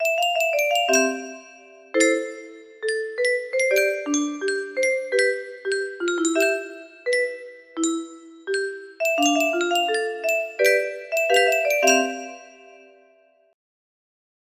two music box melody